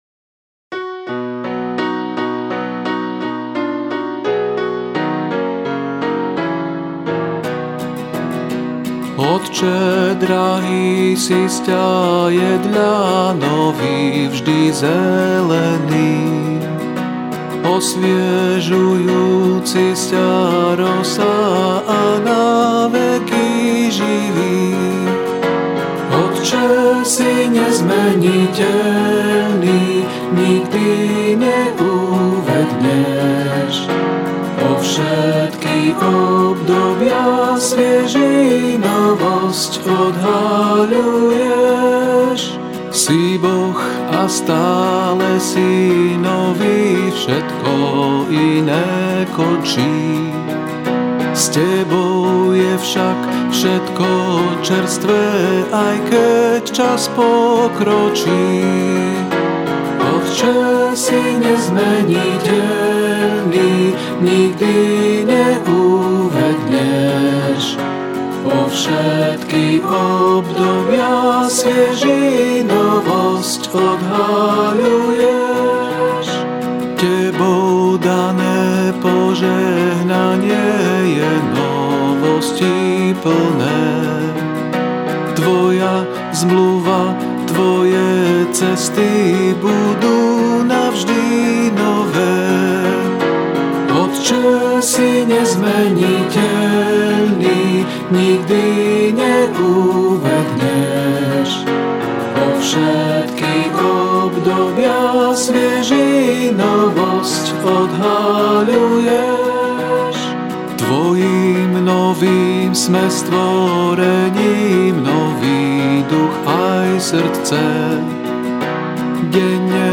C大調